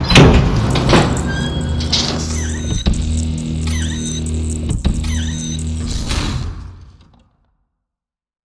retract.wav